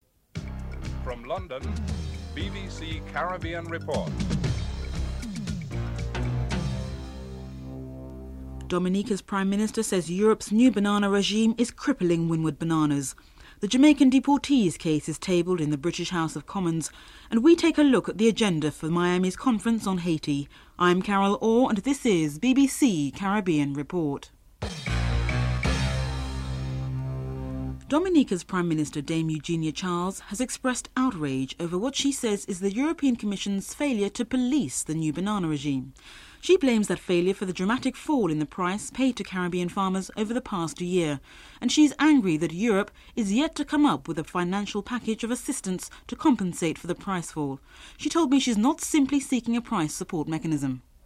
The report features excerpts of Madden’s presentation in parliament where he highlights the case of a pregnant Jamaican woman married to a British citizen, who was kept waiting for 12 hours.
Mark Entwistle, the Canadian Ambassador in Cuba, is interviewed and talks about the embargo as it pertains to Canada’s trade relationship with Cuba (8:33-11:13)